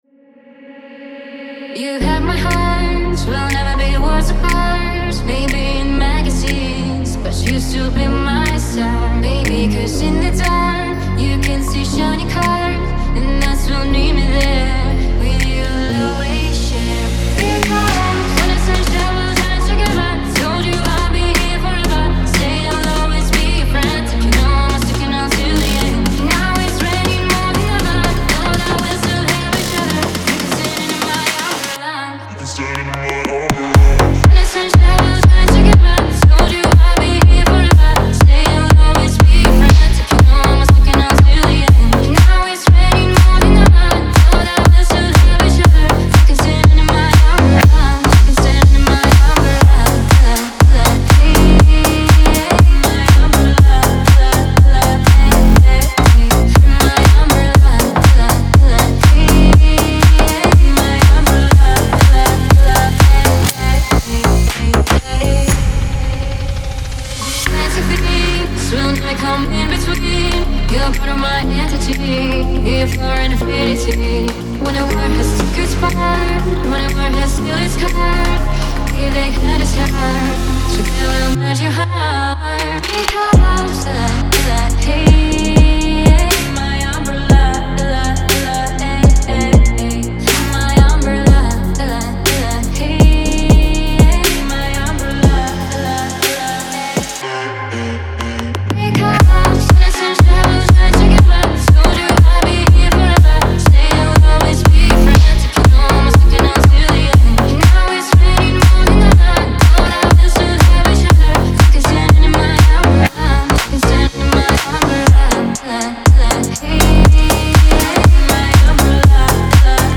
динамичная поп- и R&B-композиция
наполненная энергией и позитивом.